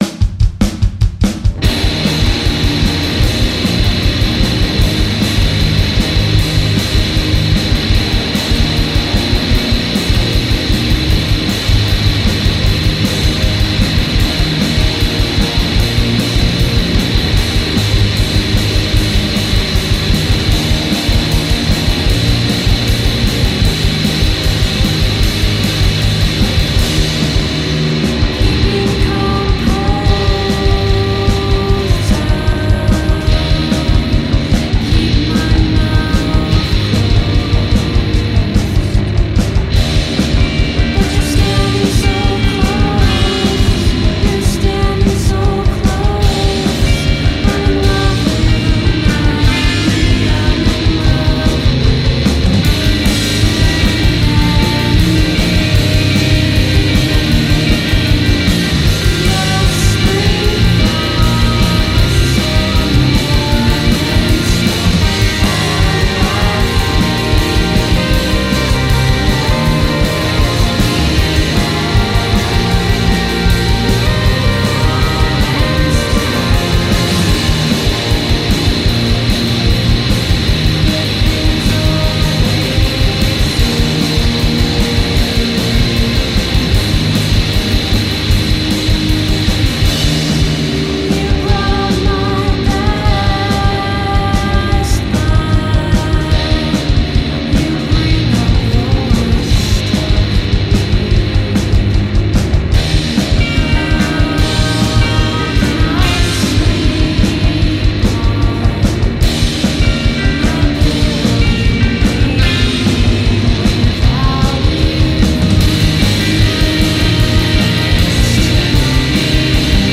shoegaze